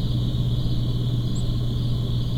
[nfc-l] Unknown Warbler
of Palm Warbler, but it's a bit high for that I think.